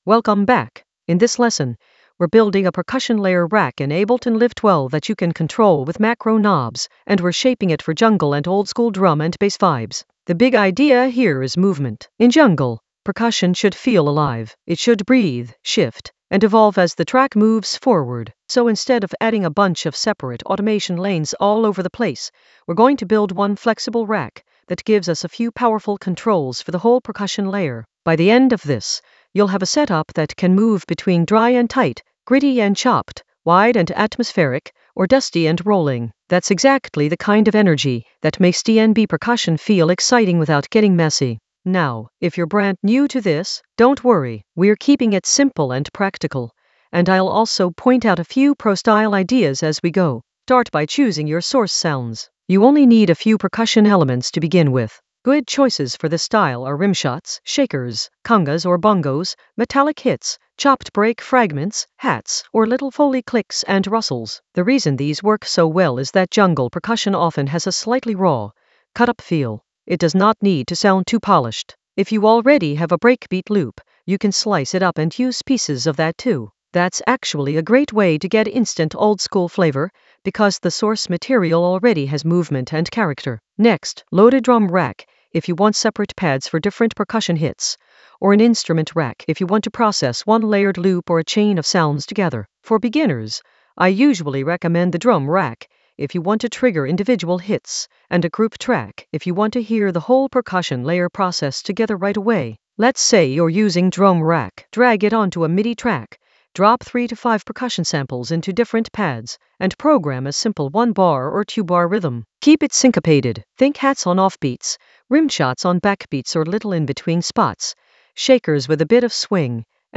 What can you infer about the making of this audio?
The voice track includes the tutorial plus extra teacher commentary.